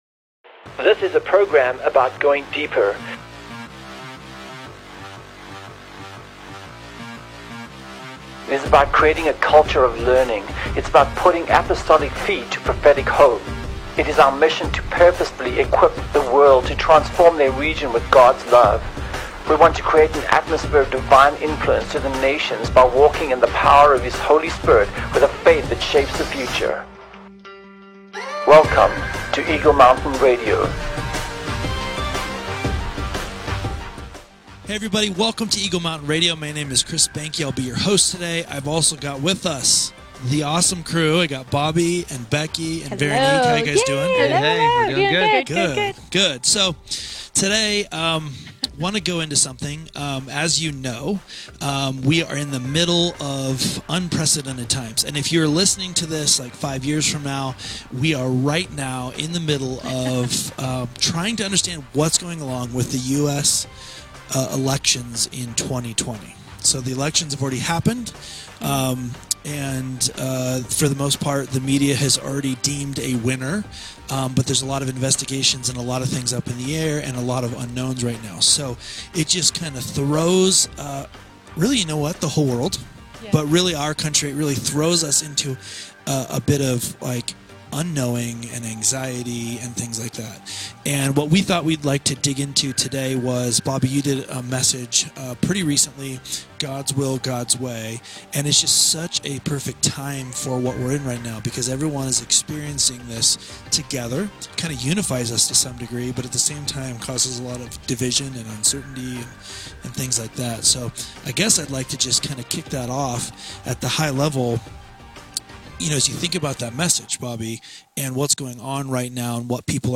Join our panel as they discuss these incredibly relevant topics.